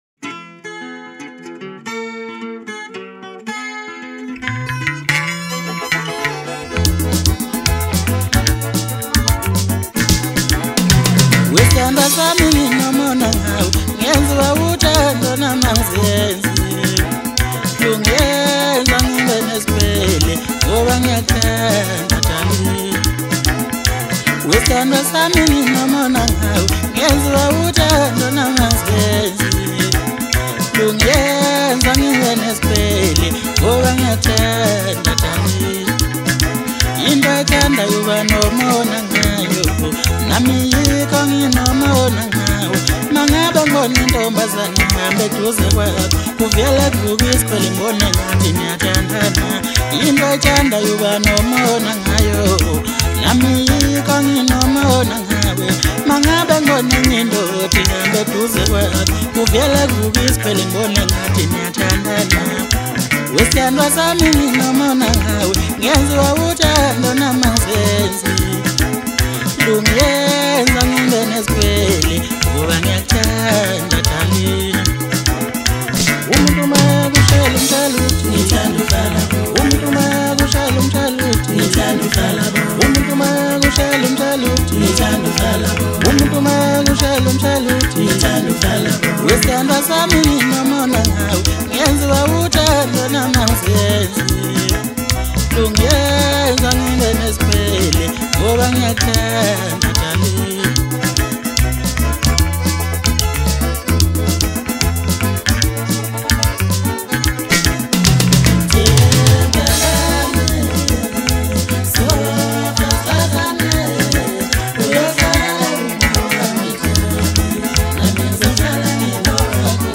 Maskandi track
guitar